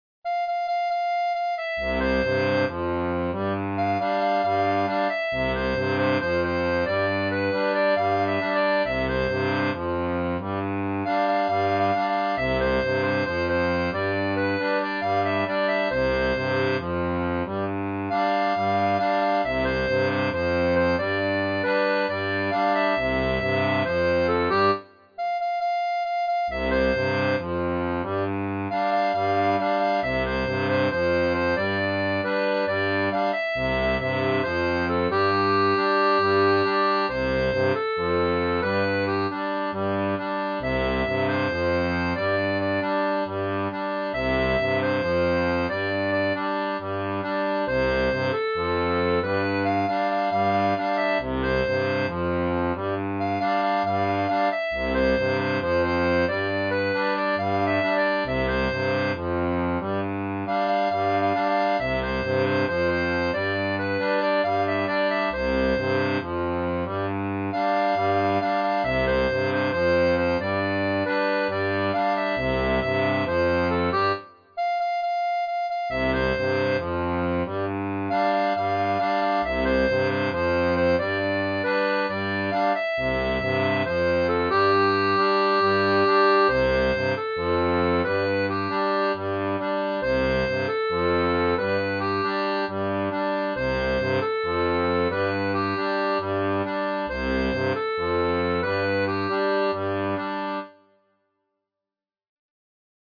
• Un fichier audio basé sur la rythmique originale
Pop-Rock